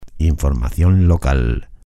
Locución gratuita para programas de televisión. Noticias locales.
noticias_locales_locucion_television_locutortv.mp3